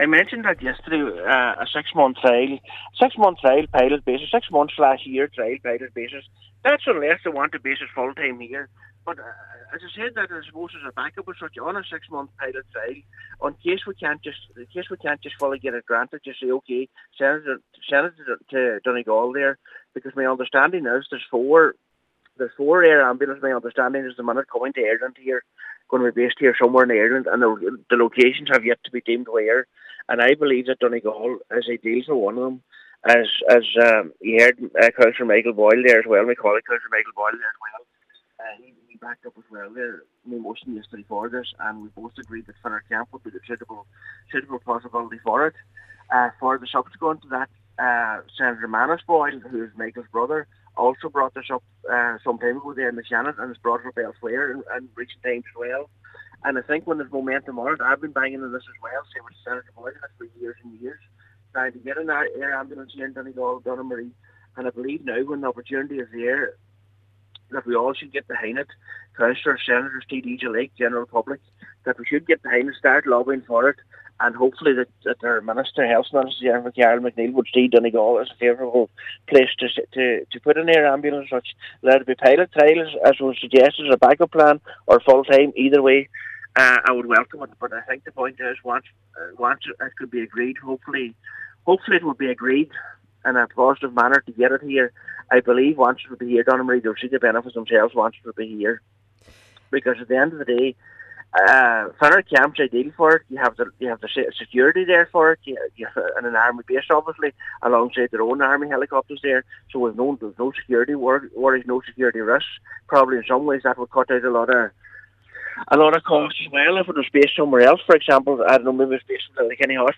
Cllr Michael McClafferty says at the very least, a six-month trial should be considered: